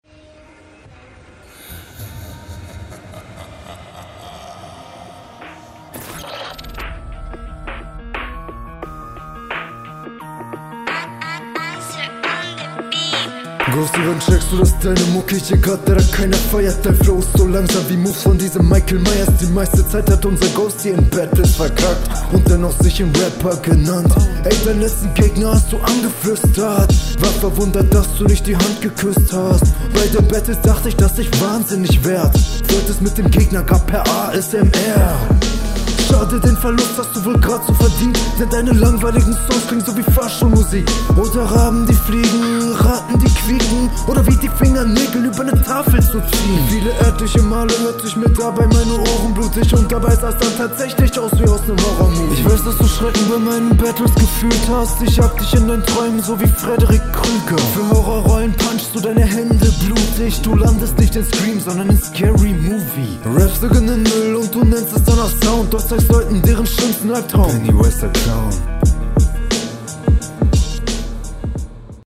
Nicht falsch verstehen, aber nimm die Kartoffel aus dem Mund :D. Stabile runde. Flow passt.